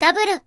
Added Zundamon voicepack